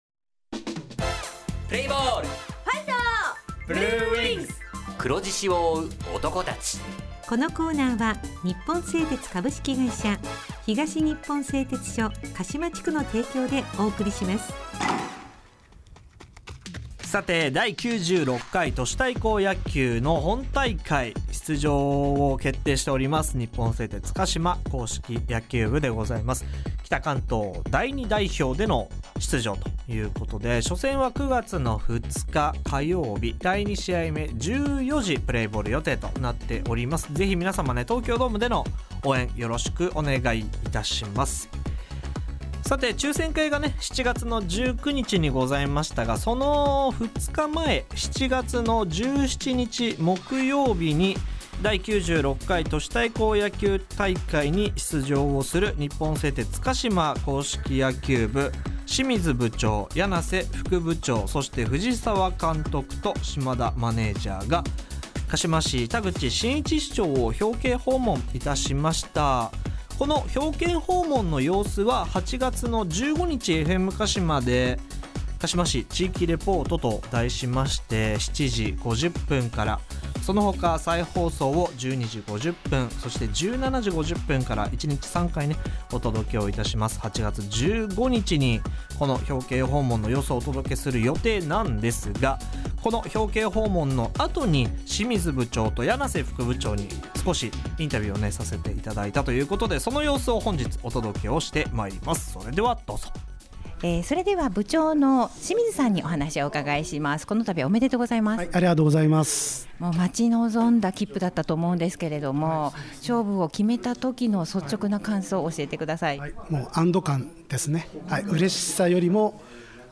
地元ＦＭ放送局「エフエムかしま」にて鹿島硬式野球部の番組放送しています。
《インタビュー》